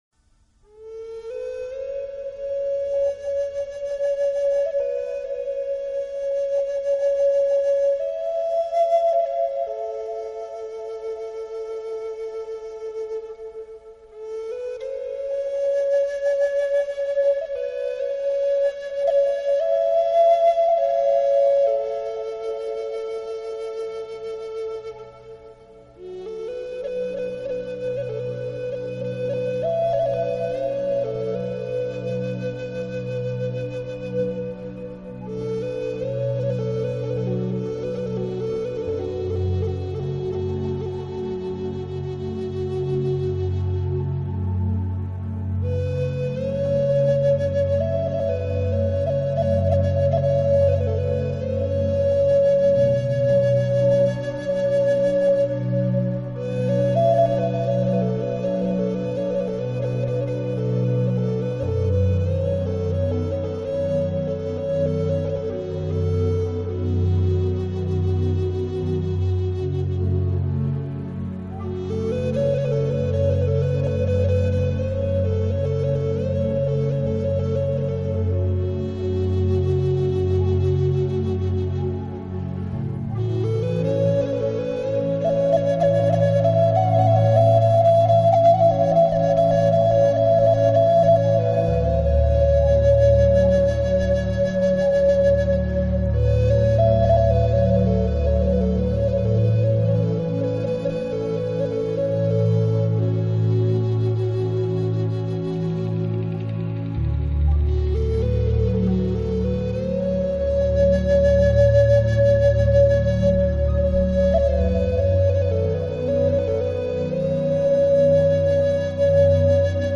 新世纪纯音乐
喜欢这种音乐，柔缓而绵延，优美而迷人，勾住了你的心魄，刺激了你的思绪，释放了你的
在音乐中，在长笛和中提琴的搀扶下，钢琴的弹奏下，情绪不断的低沉、柔软再凝结……